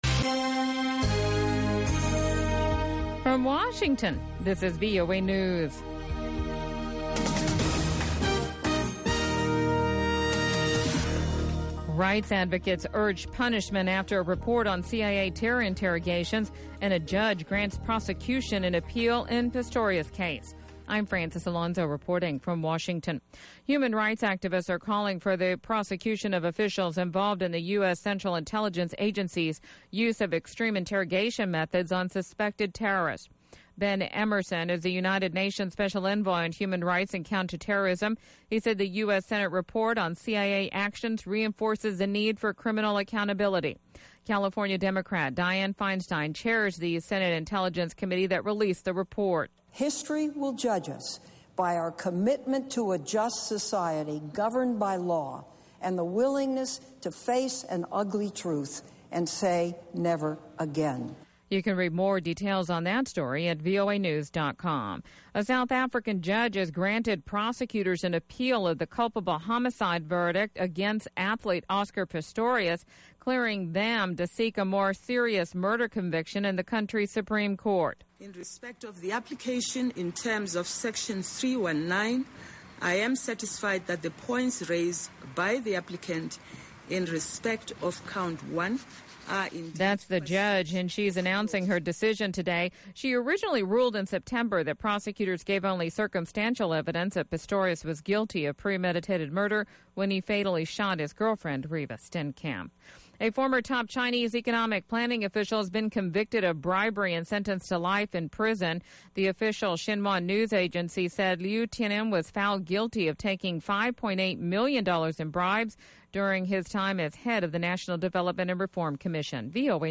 pan-African music